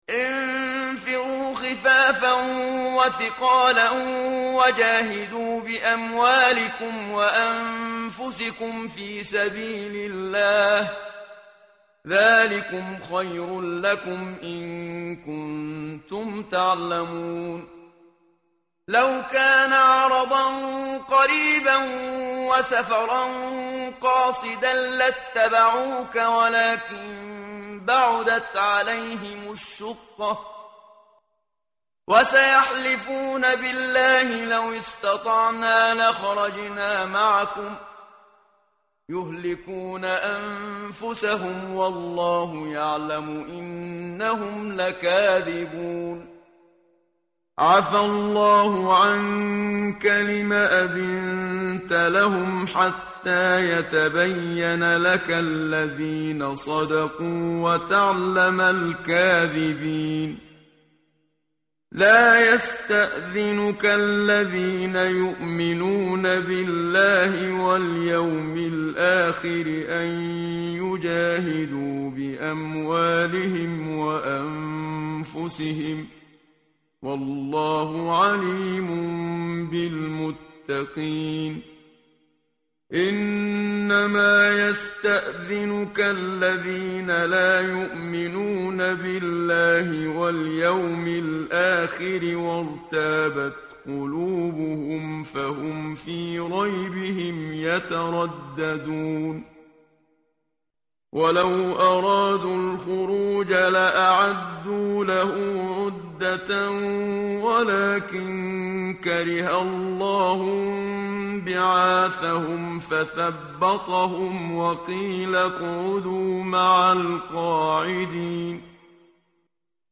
متن قرآن همراه باتلاوت قرآن و ترجمه
tartil_menshavi_page_194.mp3